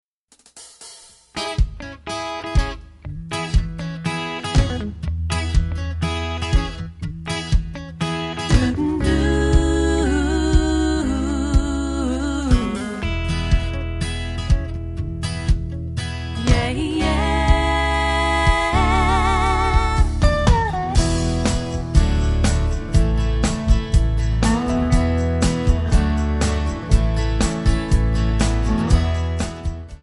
Backing track Karaoke